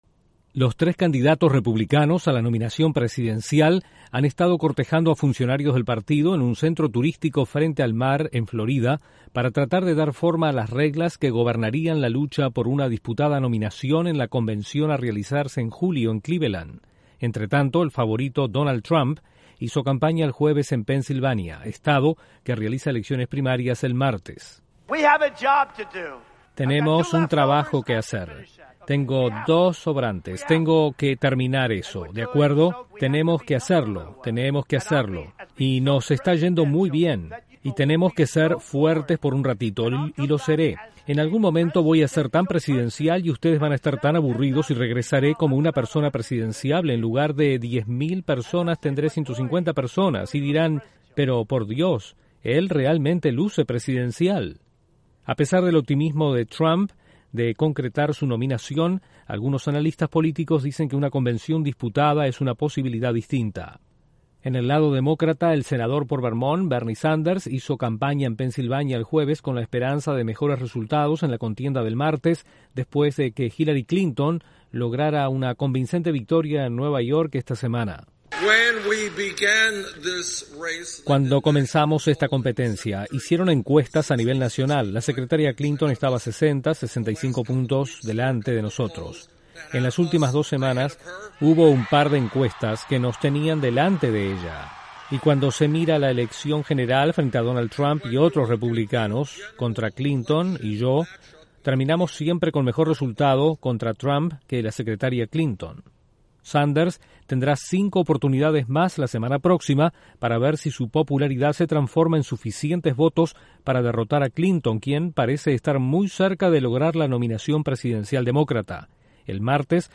Los candidatos republicanos tratan de influenciar las reglas para una convención disputada. Desde la Voz de América en Washington informa